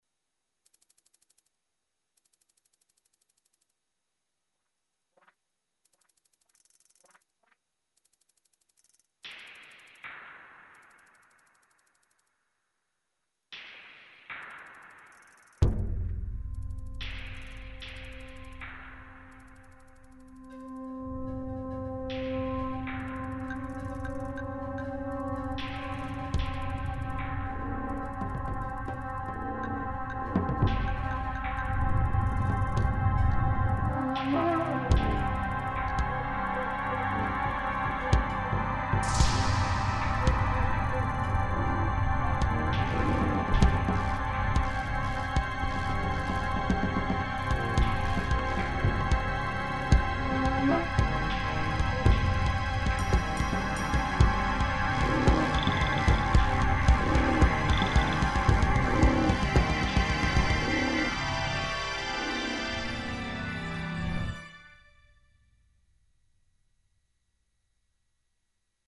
ambient, mysterious and threatening ( percussions)